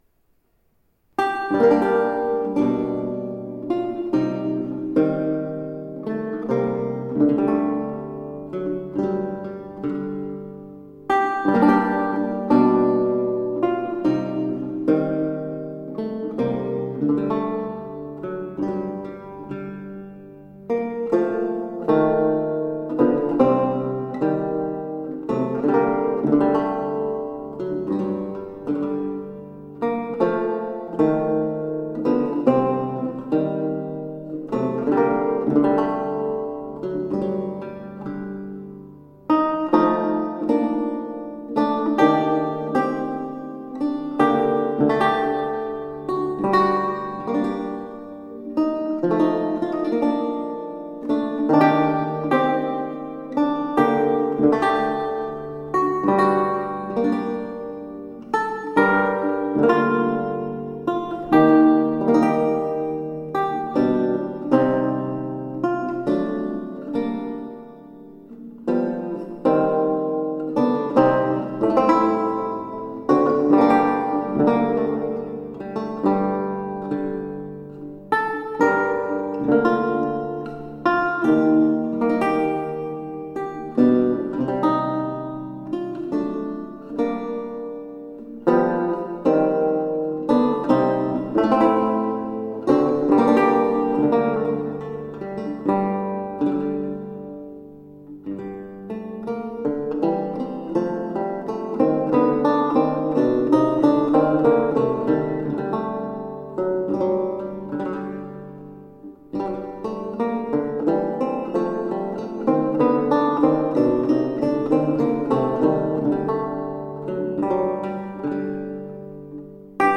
Virtuoso english lutenist and guitarist.
Recorded in St. Bartholomew's Church, Otford.
Classical, Renaissance, Instrumental